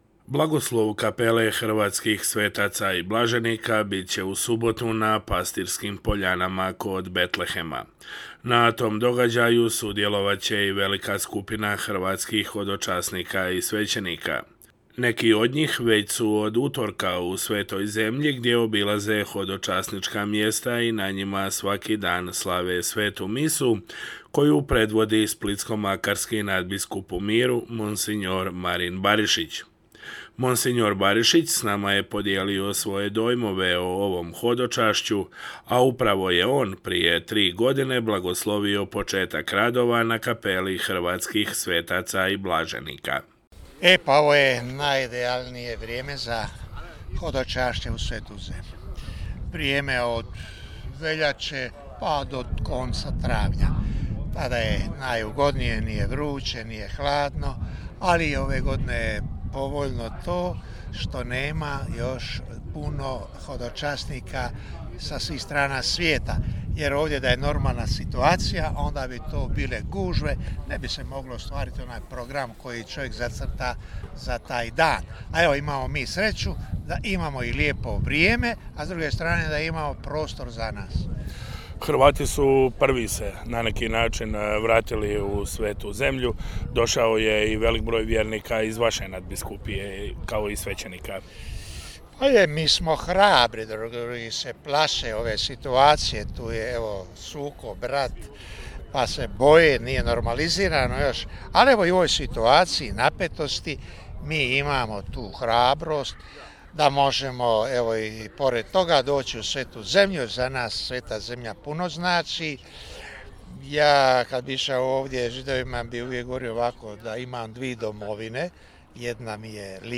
Mons. Barišić s nama je podijelio svoje dojmove o ovom hodočašću, a upravo je on prije tri godine blagoslovio početak radova na Kapeli hrvatskih svetaca i blaženika.